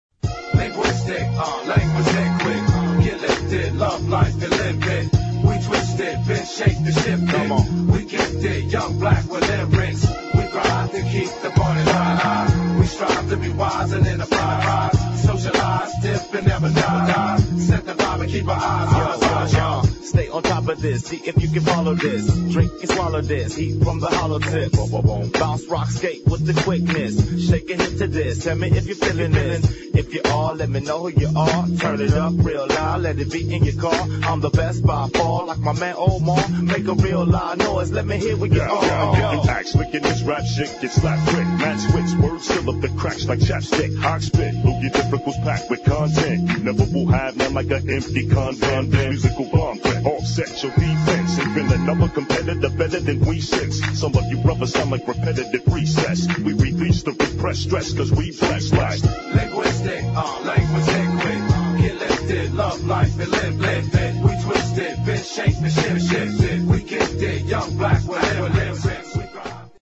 [ HIP HOP ]